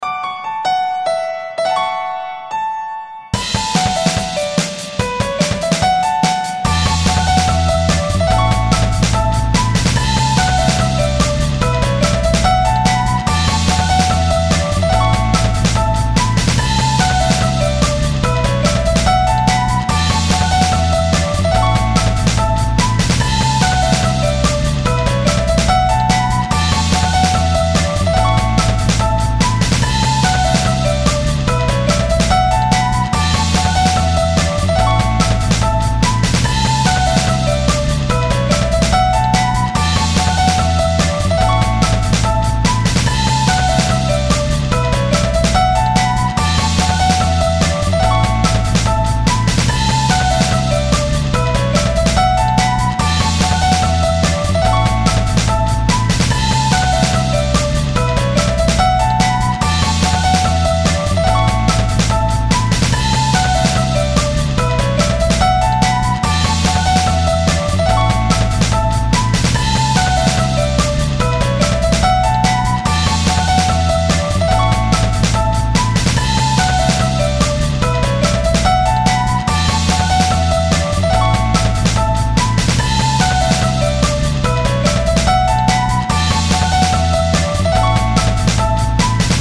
Home > Music > Pop > Bright > Running > Chasing